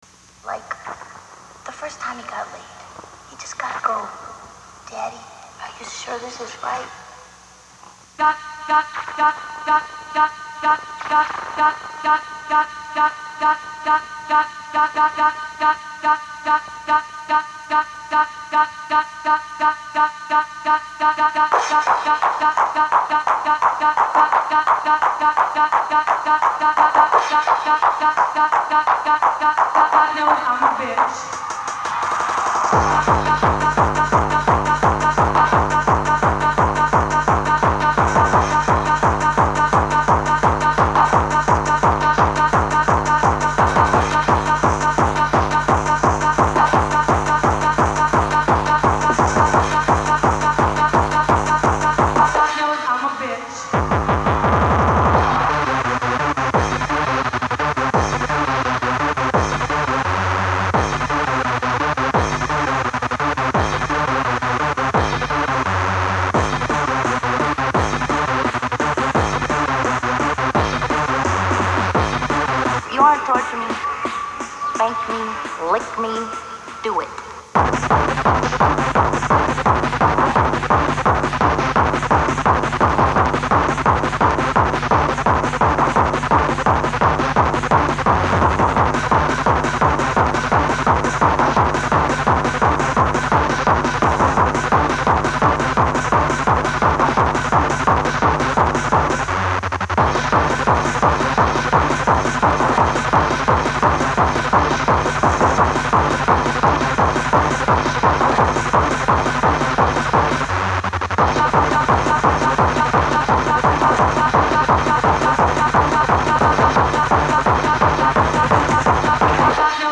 The mix is hardcore and drum & bass.